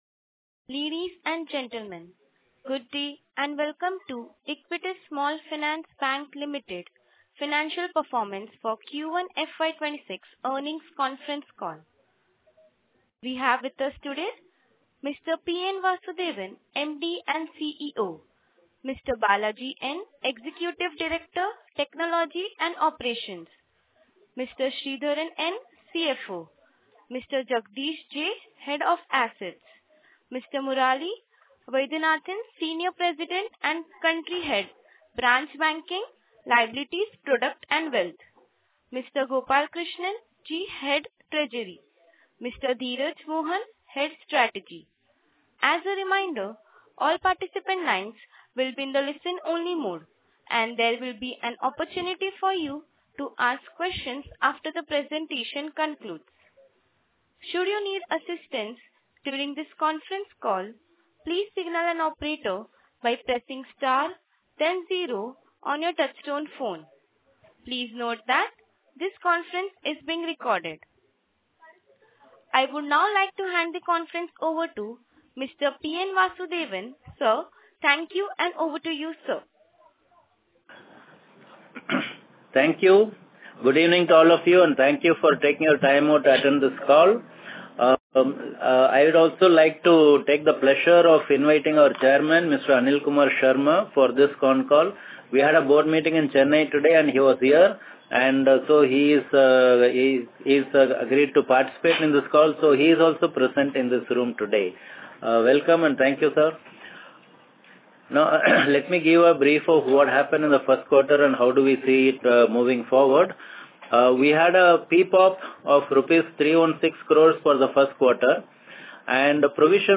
Earnings Call Audio